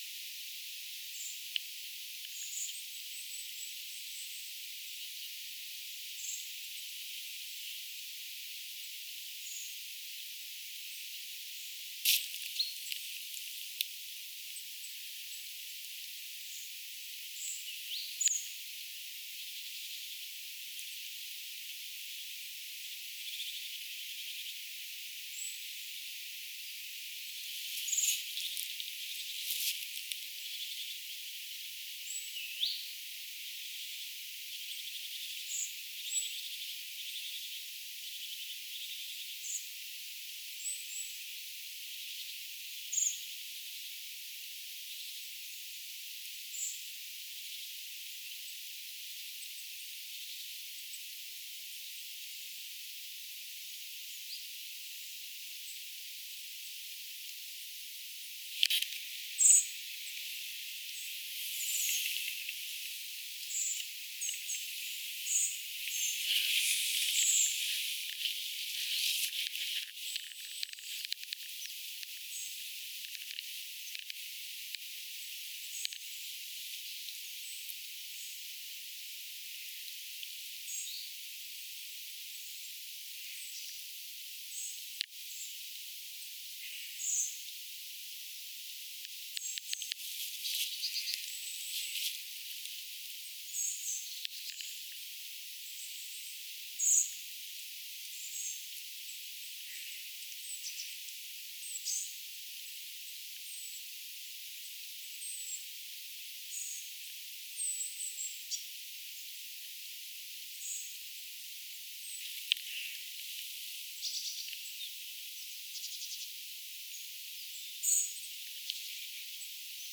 löytyykö tästä mandarinuksen ääniä
Että tuo sirinä-ääni olisi hennompi ja korkeampi
voisiko_tassa_olla_sinensiksen_aania.mp3